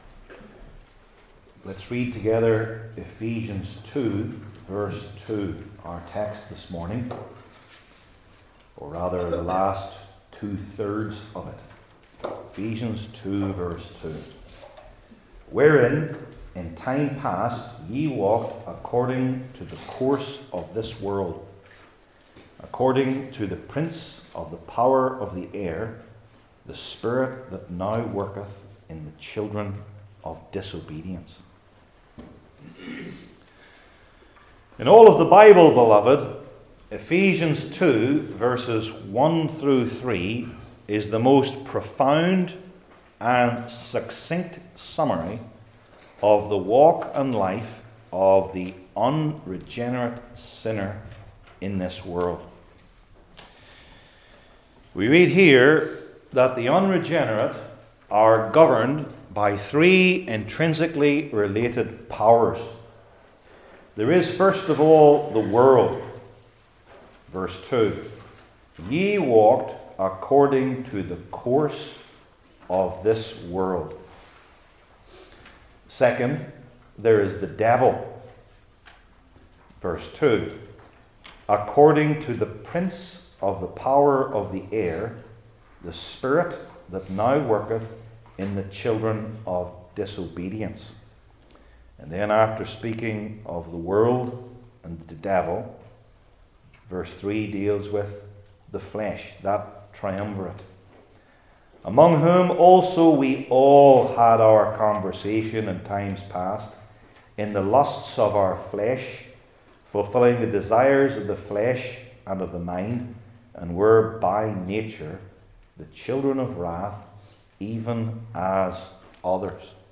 New Testament Individual Sermons I. The Subjects of Satan’s Kingdom II.